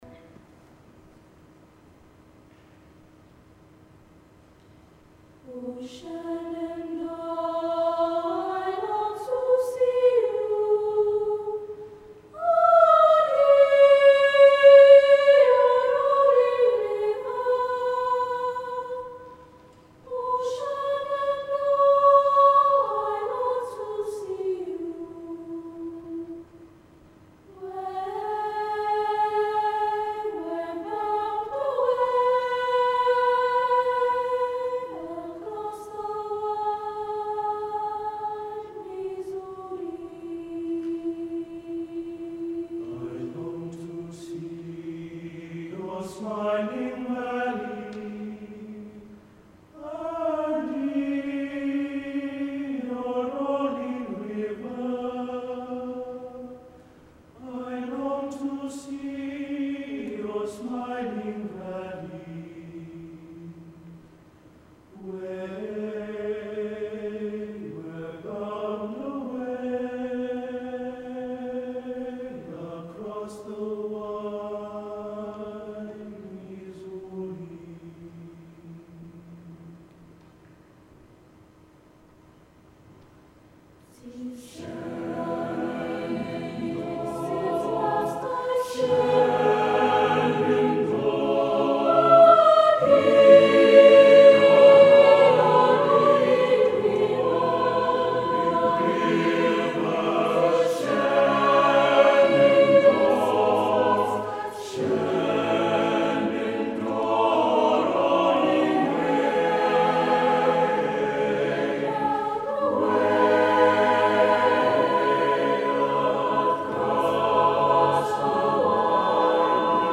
Recordings from The Big Sing National Final.
The Sweet Sixteen Aorere College Shenandoah Loading the player ...